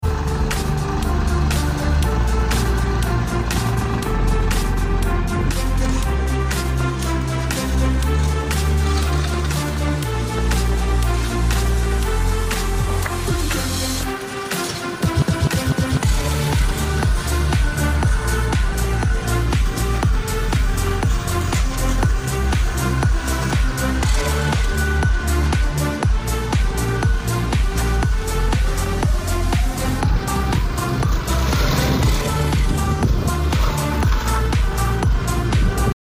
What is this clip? DELIXI mesin rumput, mesin rumput